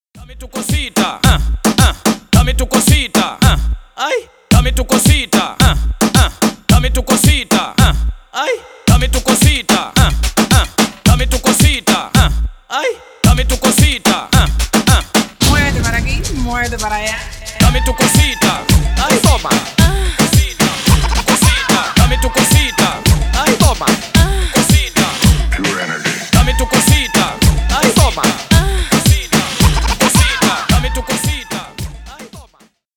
Стиль: reggaeton, moombahton
Зажигательные Заводные Энергичные Ритмичные
Танцевальные